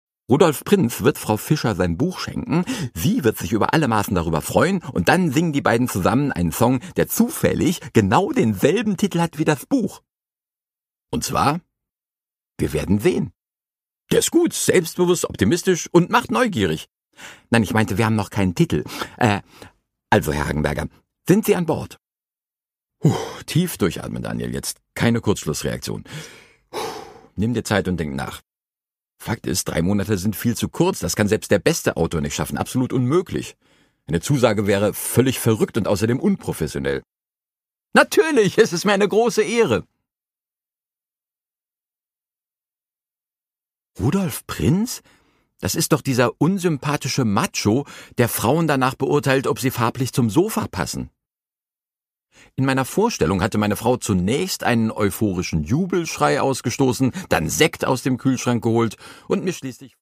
Produkttyp: Hörbuch-Download
Gelesen von: Moritz Netenjakob